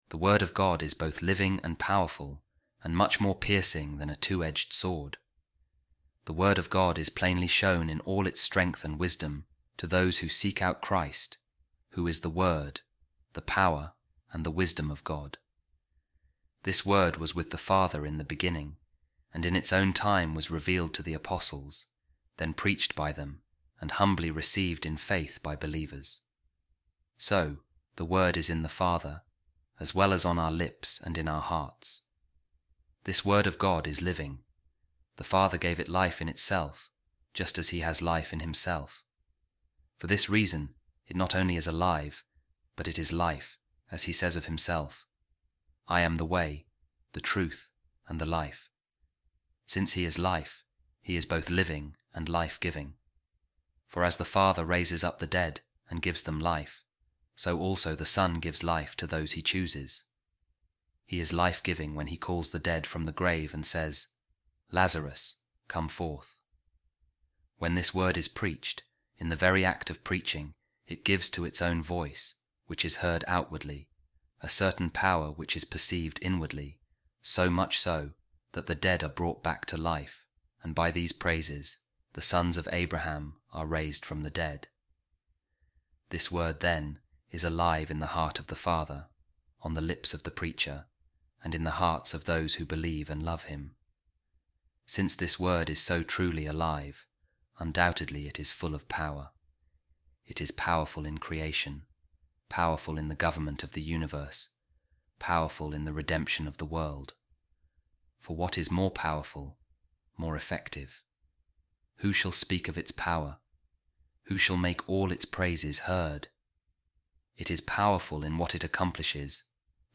A Reading From The Works Of Baldwin Of Canterbury | The Word Of God Is Living And Active